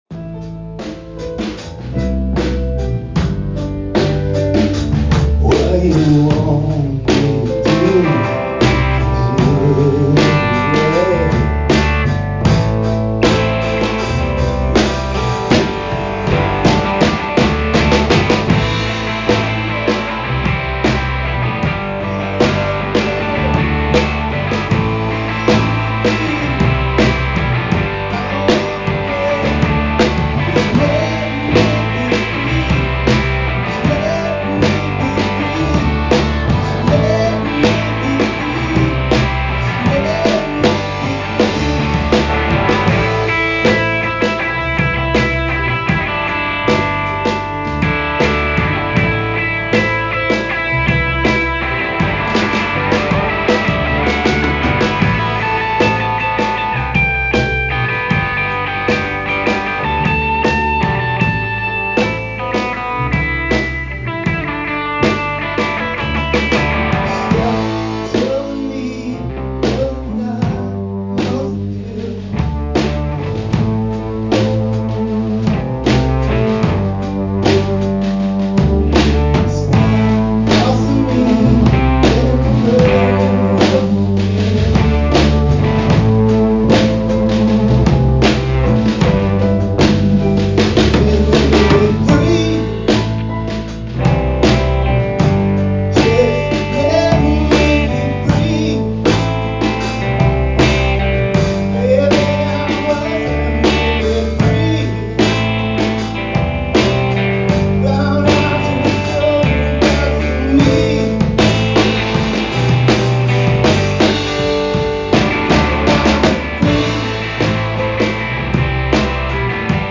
Piano Band Jam